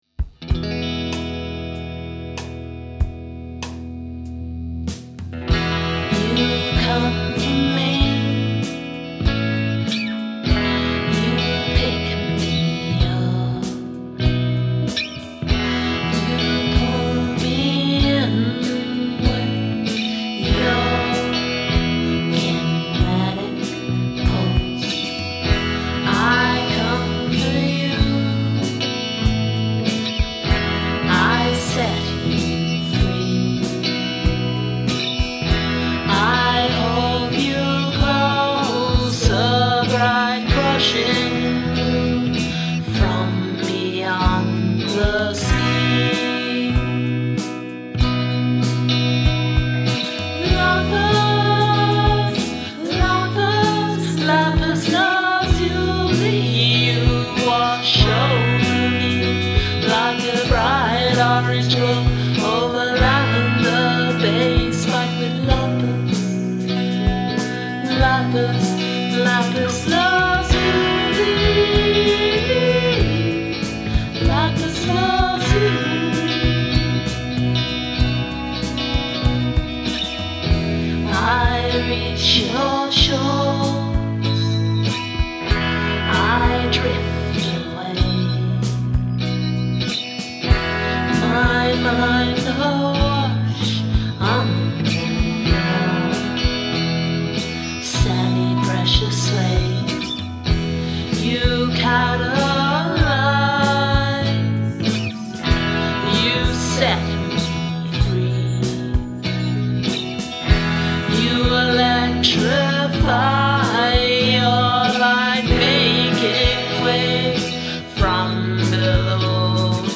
Gradual emphasis of repetitions
I think it was that strummy electric guitar.
Guitars sound great and the layered vocals work really well.